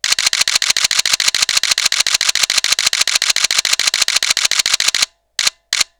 Sorozat fényképezés, sebesség
DX gyors RAW sorozat 12 bit
D3X_continousDXhi12bit.wav